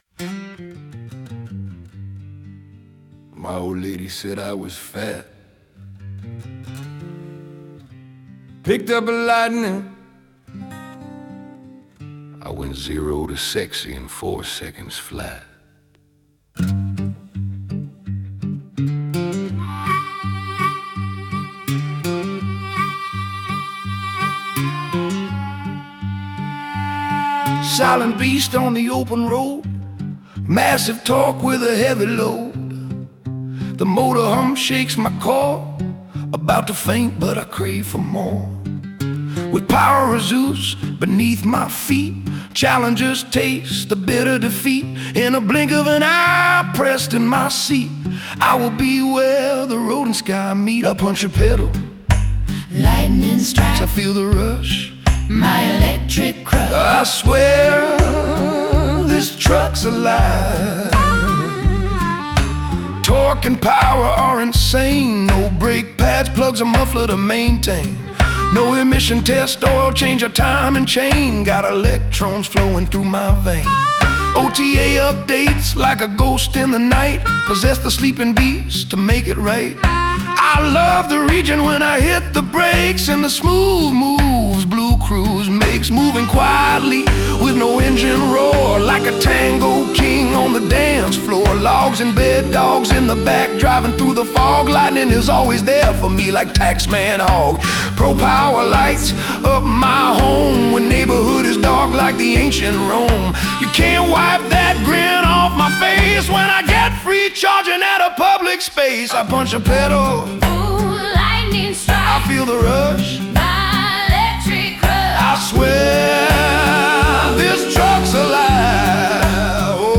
The Blues version: